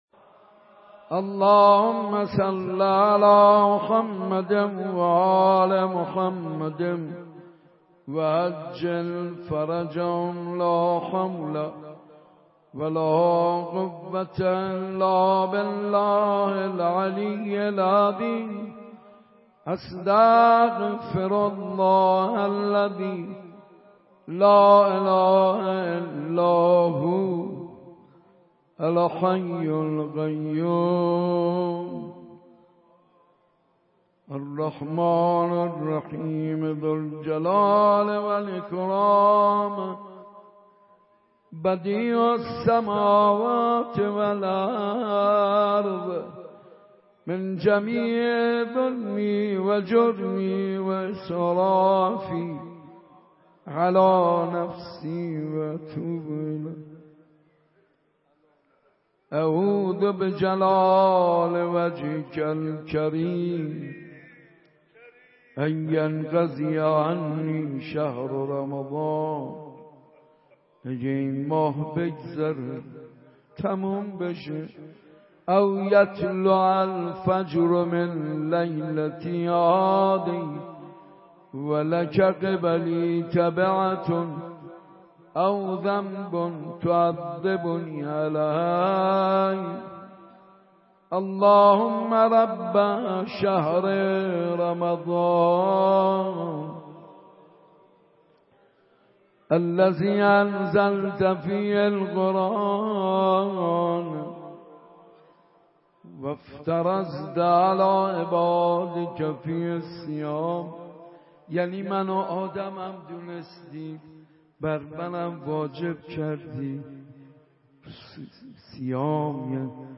مداحی های شب های هفتم تا نهم ماه مبارک رمضان97
مسجد ارک تهران